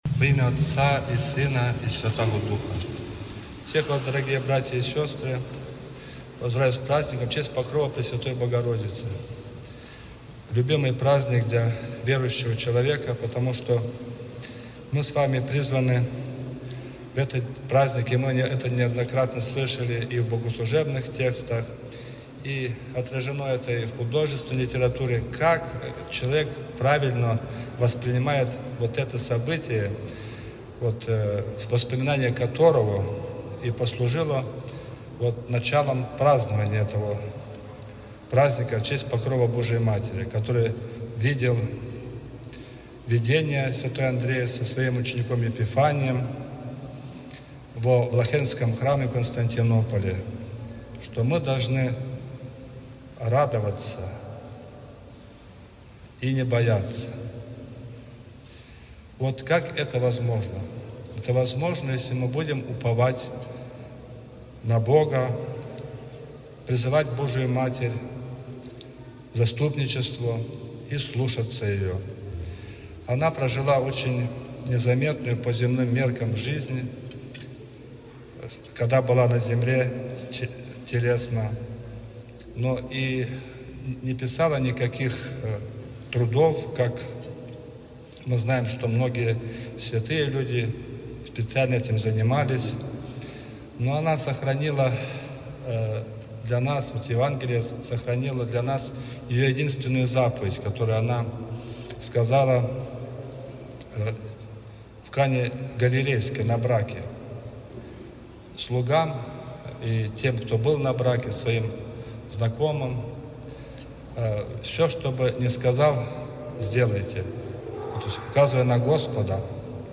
Проповедь-2.mp3